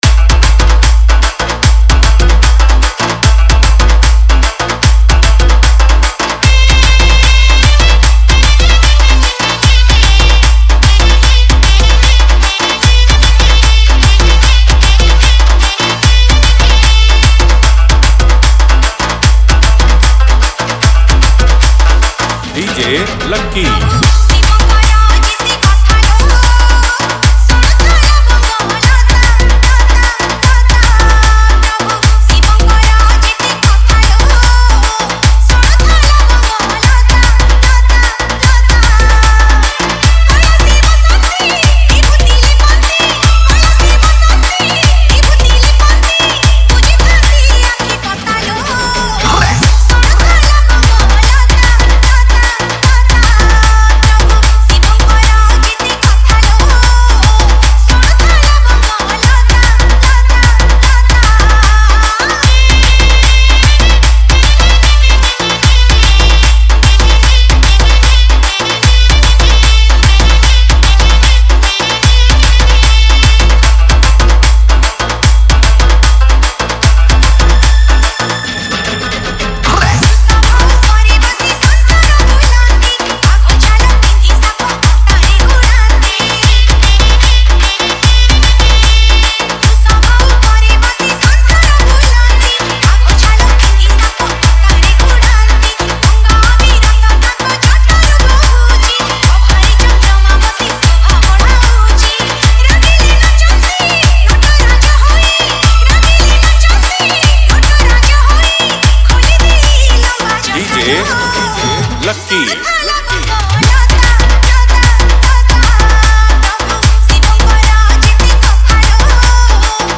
Shivratri Special DJ Remix Songs